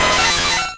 Cri d'Électhor dans Pokémon Rouge et Bleu.